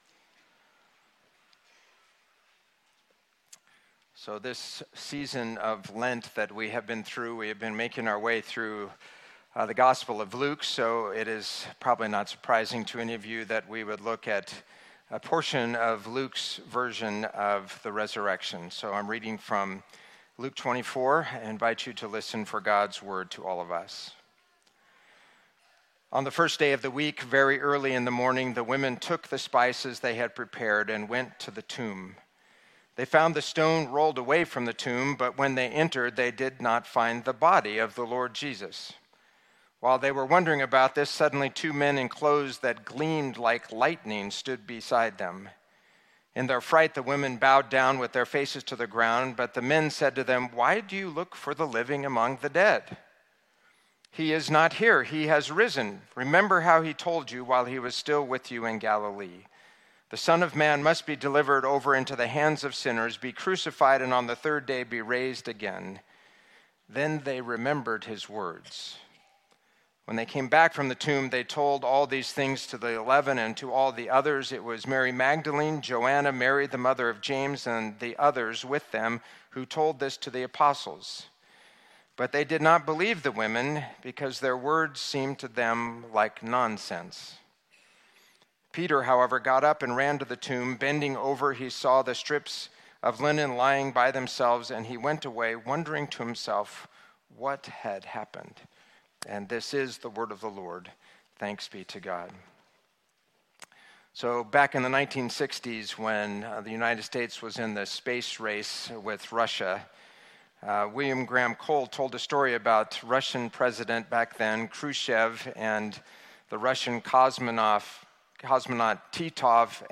Sermon
Easter Sunday!